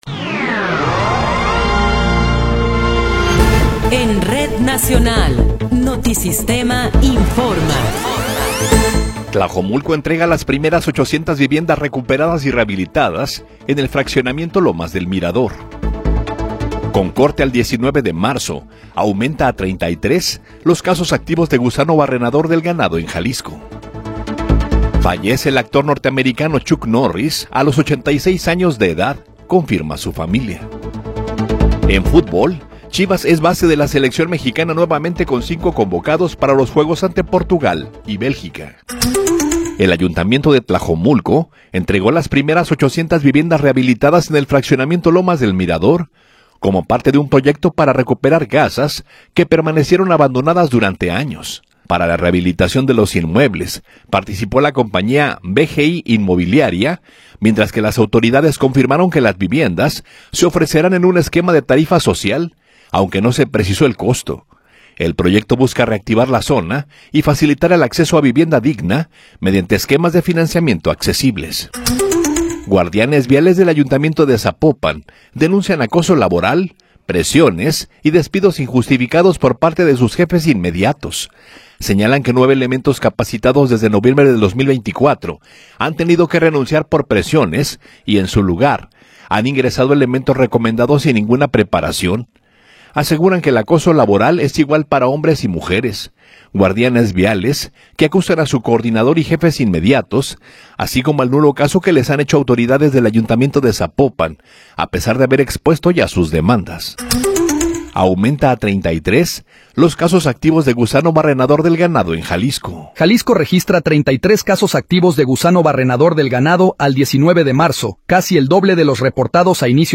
Noticiero 9 hrs. – 20 de Marzo de 2026
Resumen informativo Notisistema, la mejor y más completa información cada hora en la hora.